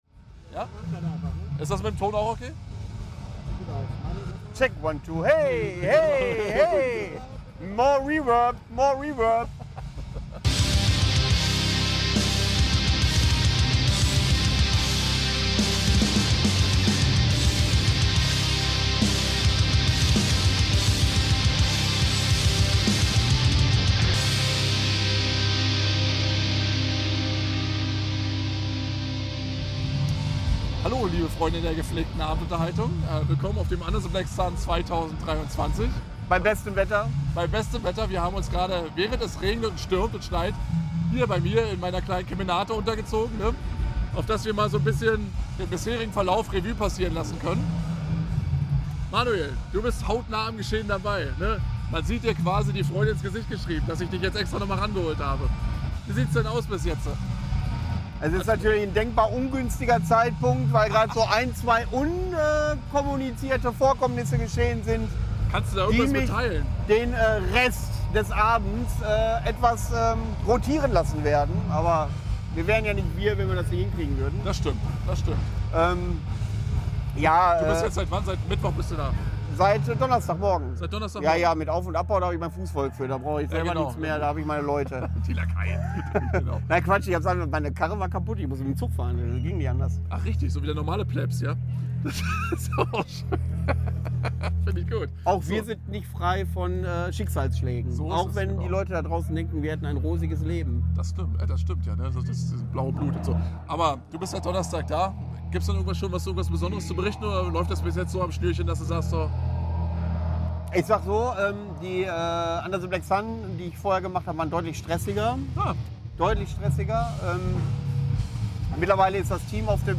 Im Laufe der Sendung werden zwei musikalische Liveclips eingespielt: Hats Barn
Da haben wir es uns natürlich nicht nehmen lassen, direkt vor Ort noch ein kleines Fazit zu ziehen.